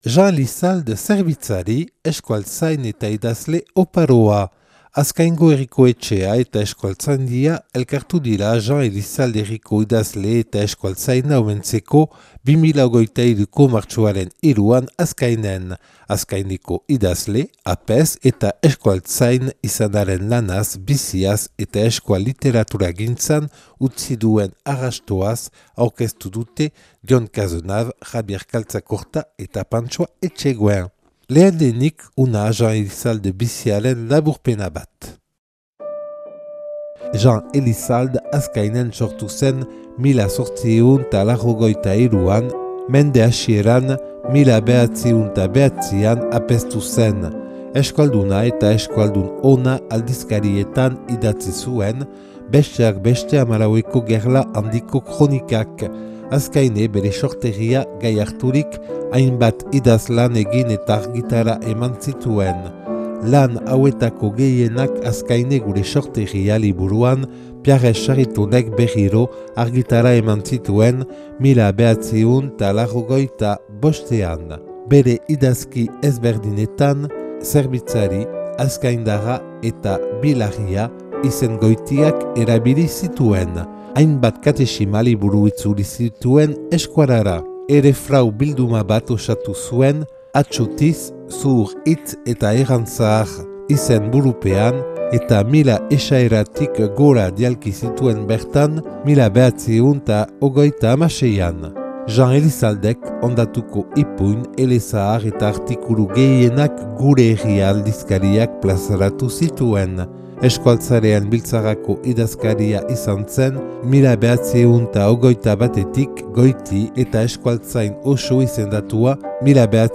Azkaingo Herriko Etxea eta Euskaltzaindia elkartu dira Jean Elissalde herriko idazle eta euskaltzaina omentzeko 2023.